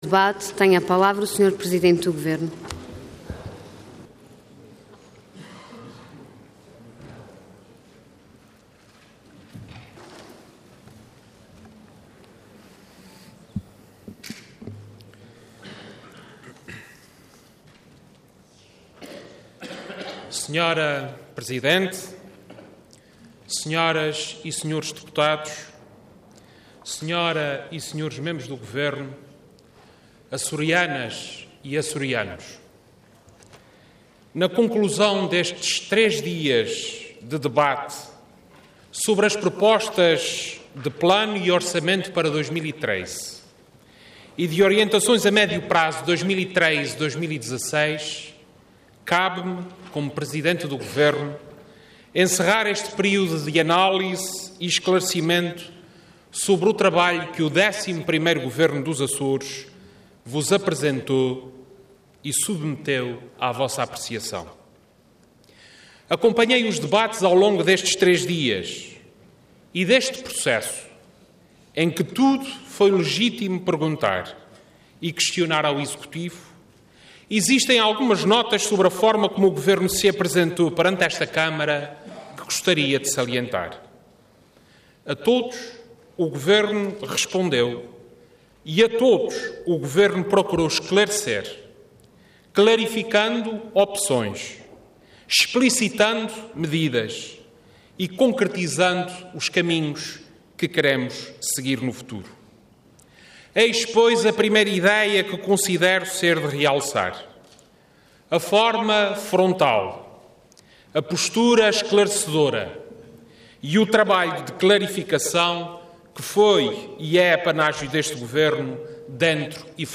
Intervenção Intervenção de Tribuna Orador Vasco Cordeiro Cargo Presidente do Governo Regional Entidade Governo